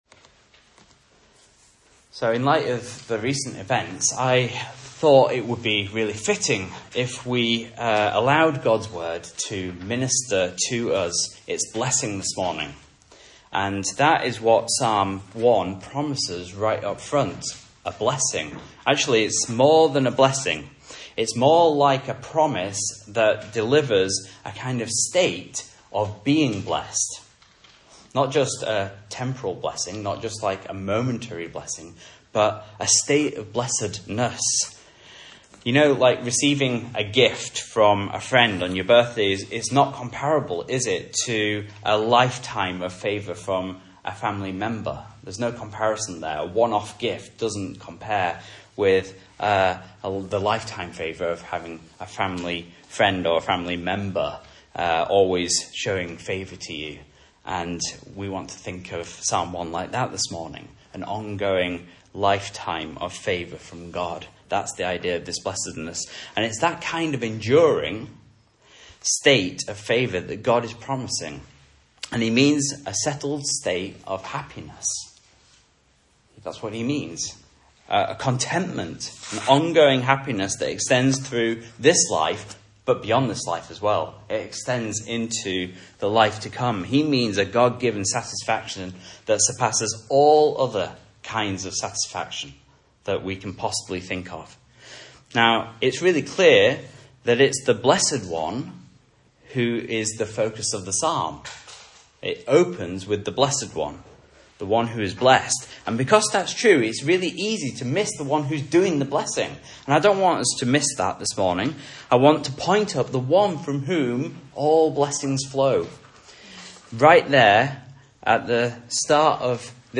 Message Scripture: Psalm 1 | Listen